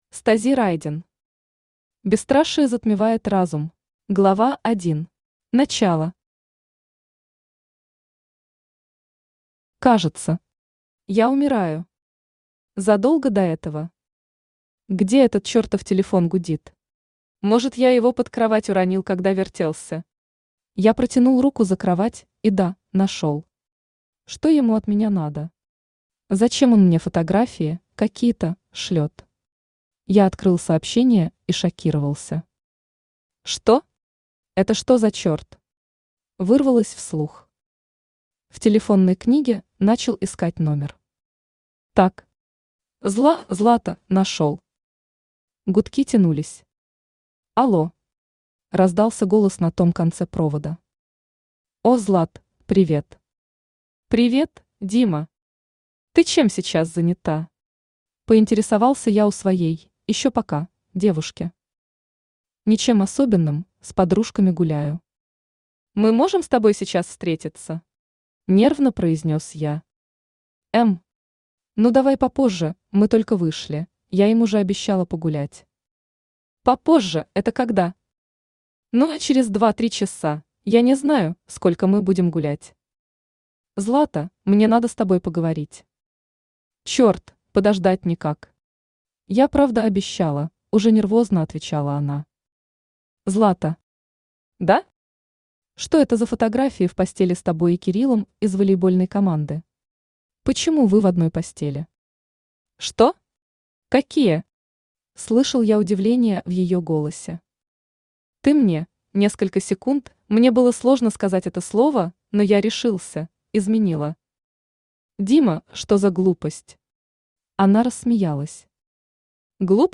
Аудиокнига Бесстрашие затмевает разум | Библиотека аудиокниг
Aудиокнига Бесстрашие затмевает разум Автор Стази Райден Читает аудиокнигу Авточтец ЛитРес.